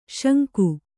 ♪ śanku